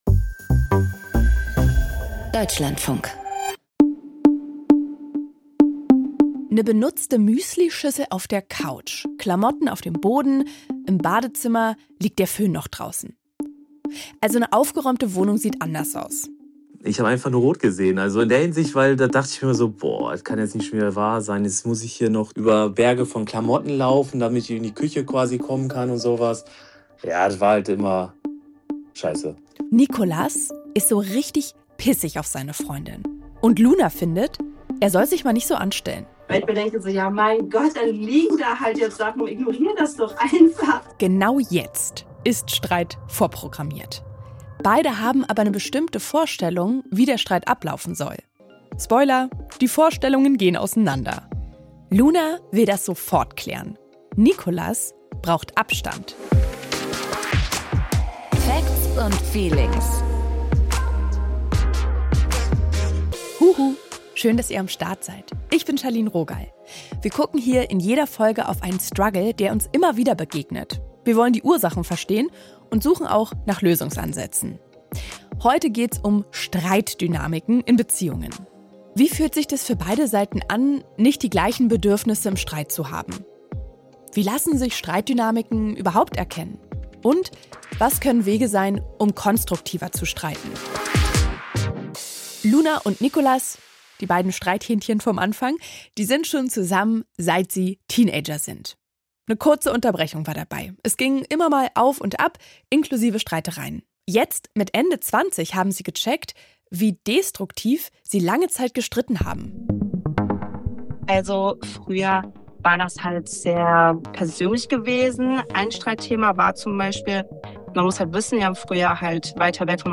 Heute gehen sie Konflikte gesünder an, sagen sie. Wie streiten auch konstruktiv geht, erklärt eine Paartherapeutin.